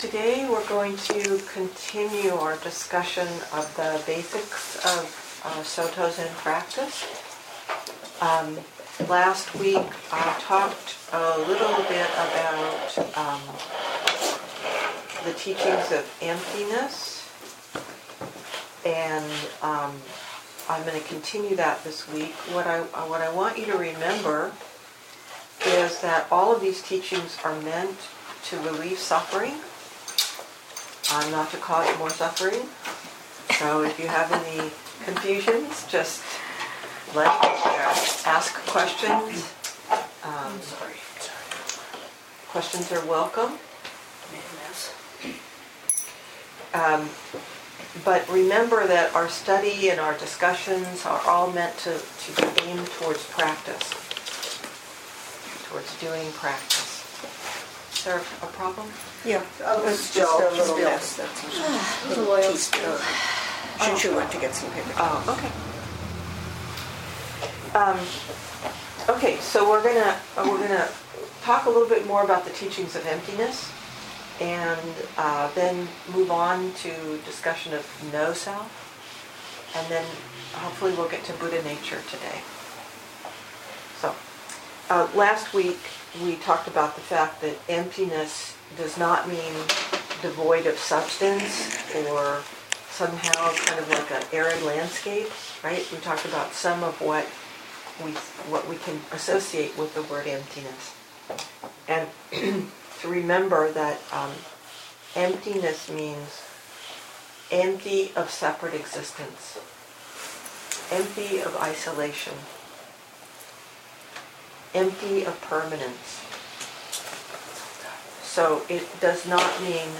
2014 in Dharma Talks